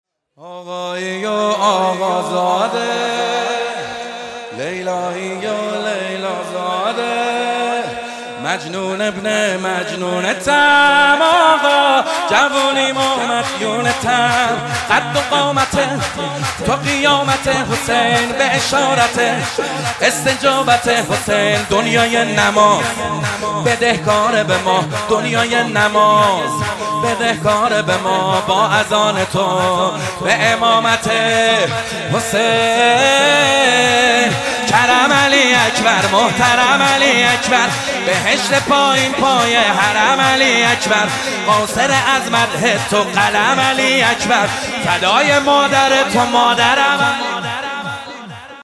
0 0 شب سوم فاطمیه دوم 1402 - شور - آقایی و آقا زاده - محمد حسین حدادیان 0 0 شب سوم فاطمیه دوم صوتی -شور - آقایی و آقا زاده - محمد حسین حدادیان 0 0 ولادت علی اکبر - شور - آقایی و آقا زاده - محمد حسین حدادیان